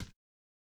Bare Step Stone Hard E.wav